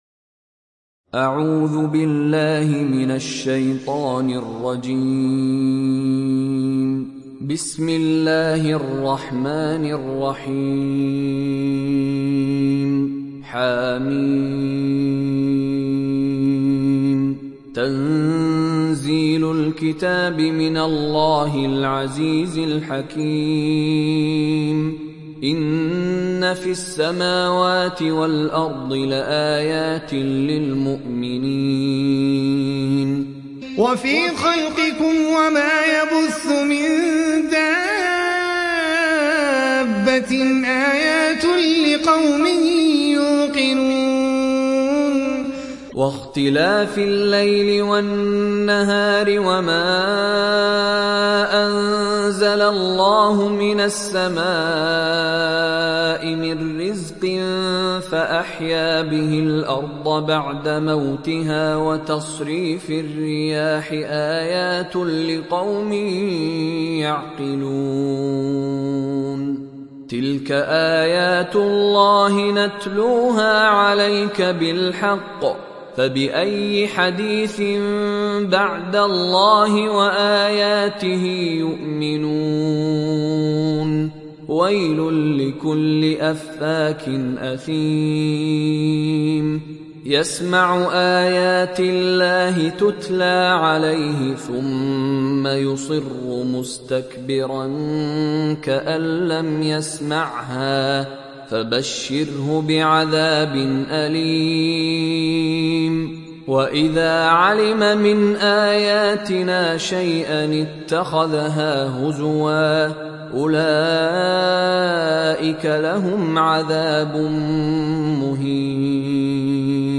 تحميل سورة الجاثية mp3 بصوت مشاري راشد العفاسي برواية حفص عن عاصم, تحميل استماع القرآن الكريم على الجوال mp3 كاملا بروابط مباشرة وسريعة